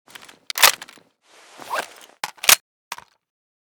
ump45_reload.ogg.bak